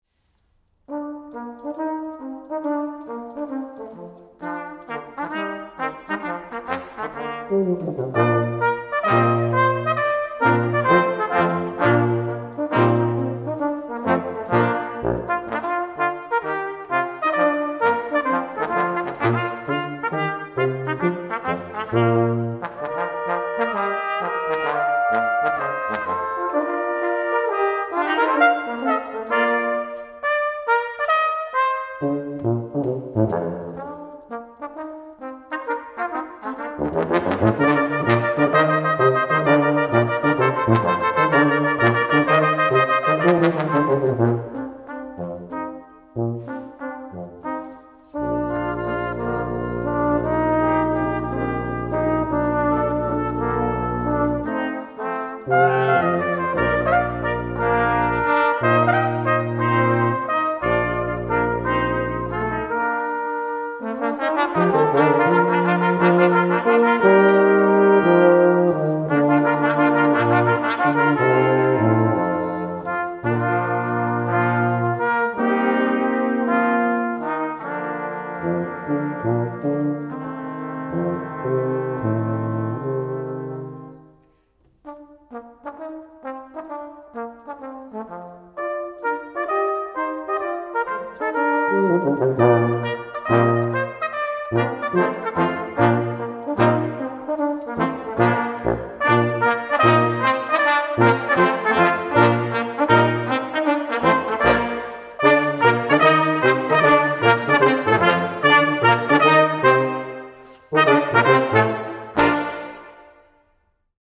Your shortcut to authentic S&DR music from 1825 can be found here.
by Queen Victoria's Consort for the Friends of the S&DR | Cheering and Appropriate Airs for a Railway Celebration (sample below Weel May the Keel Row)